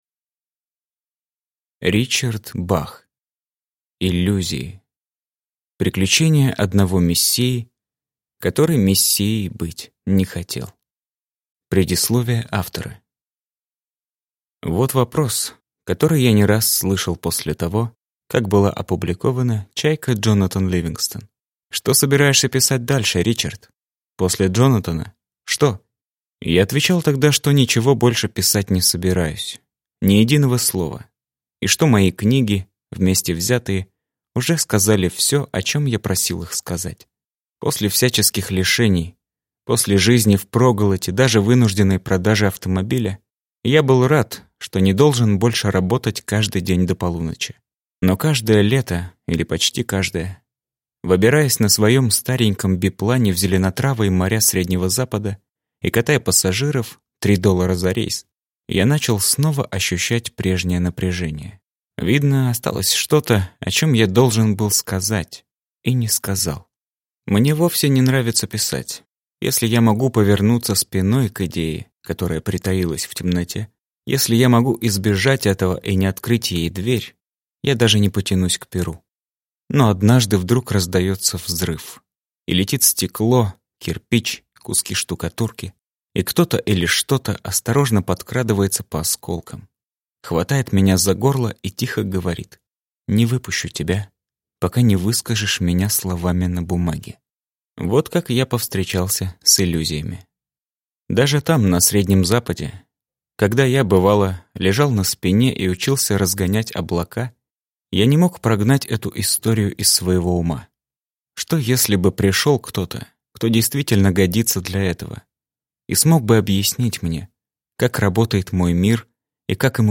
Аудиокнига Иллюзии | Библиотека аудиокниг